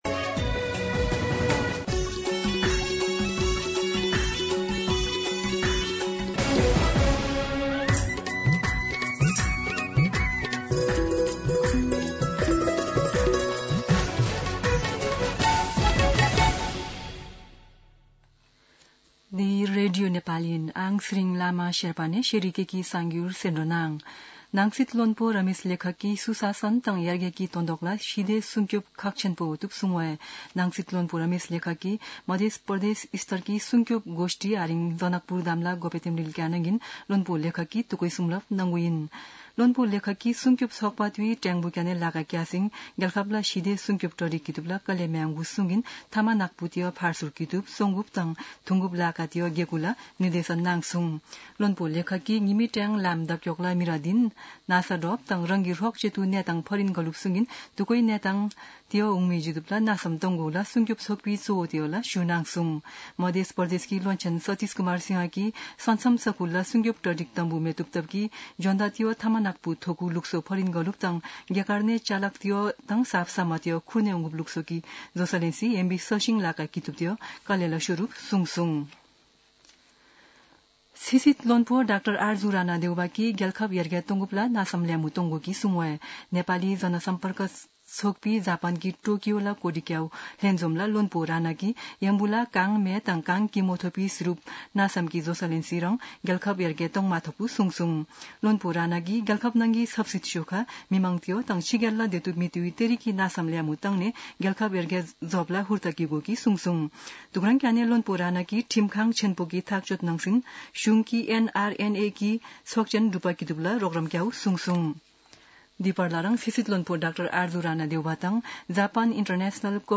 शेर्पा भाषाको समाचार : ९ जेठ , २०८२